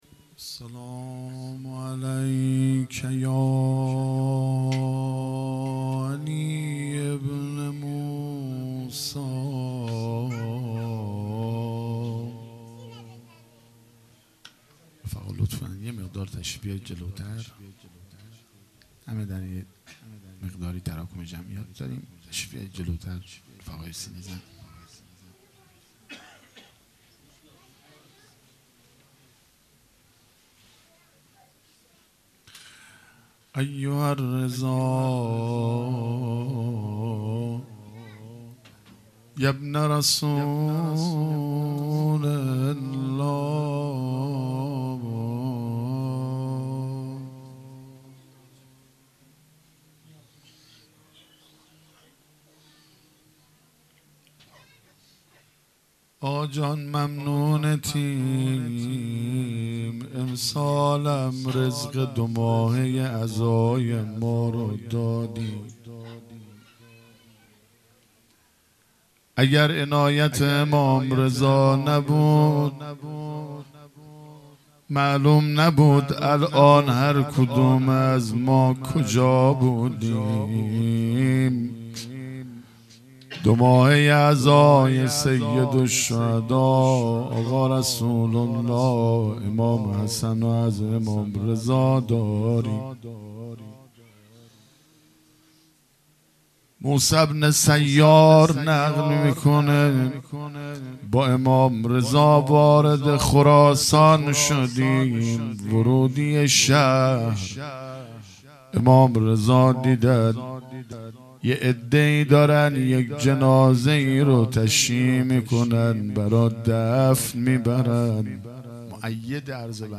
هیئت مکتب الزهرا(س)دارالعباده یزد - روضه | با حال بد بر سر کشیدی تا عبایت را مداح